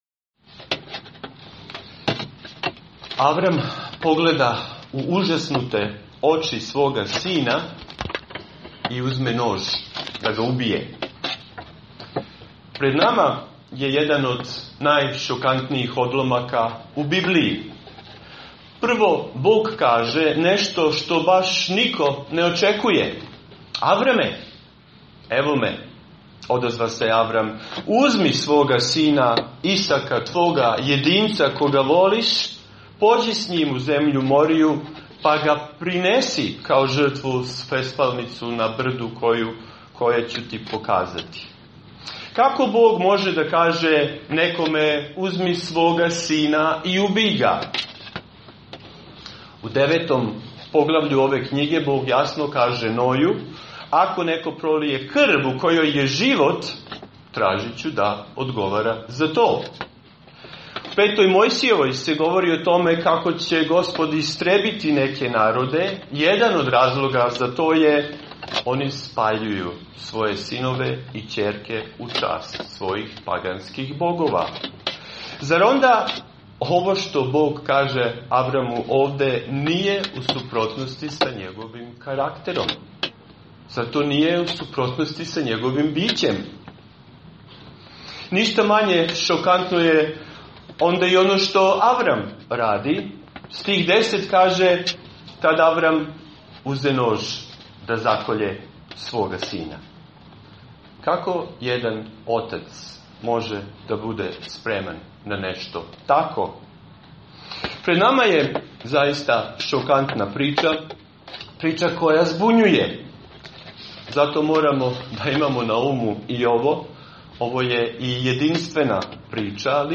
Propoved: Vera i žrtva - 1. Mojsijeva 22:1-19
Serija: Avram: otac svih koji veruju | Poslušajte propoved sa našeg bogosluženja.